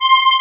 SynthPad(2)_C6_22k.wav